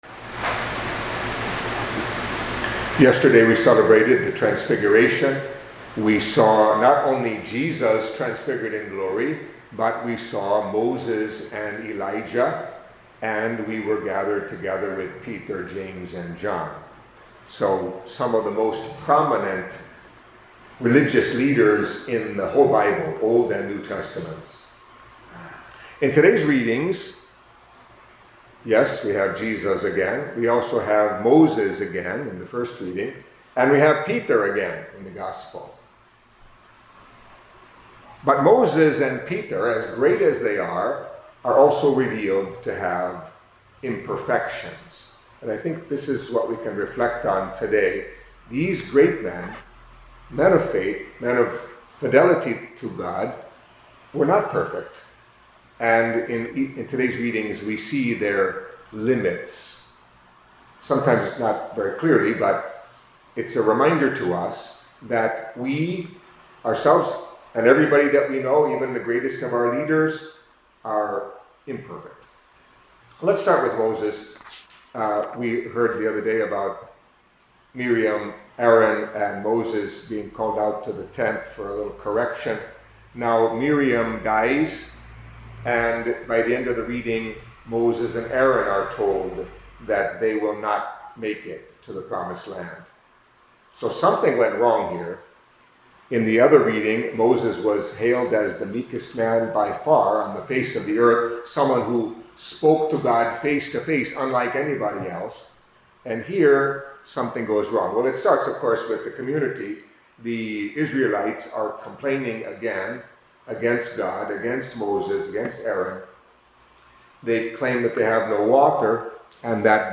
Catholic Mass homily for Thursday of the Eighteenth Week in Ordinary Time